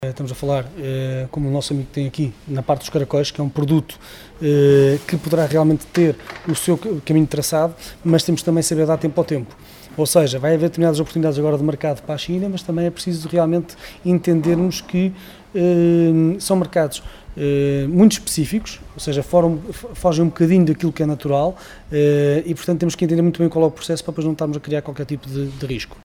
Declarações à margem da sessão de esclarecimento “Macau como Plataforma dos 3 Centros – Apoio à exportação para o mercado chinês”, que ontem decorreu em Macedo de Cavaleiros.